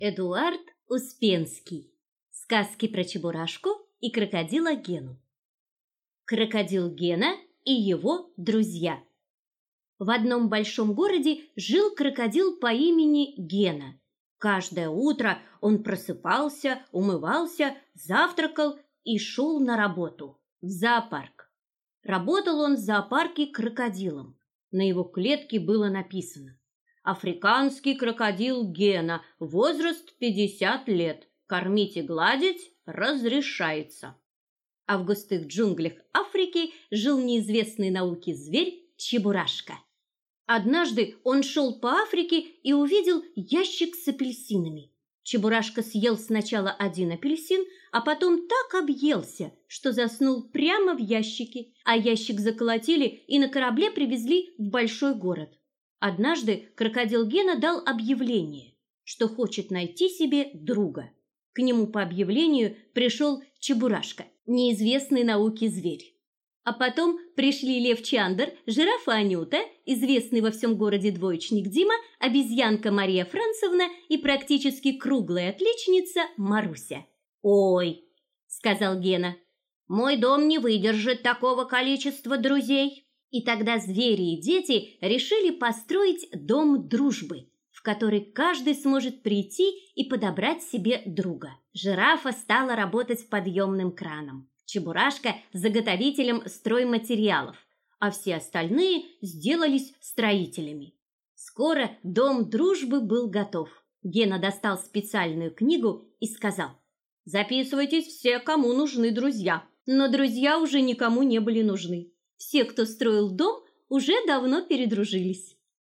Аудиокнига Сказки про Чебурашку и Крокодила Гену | Библиотека аудиокниг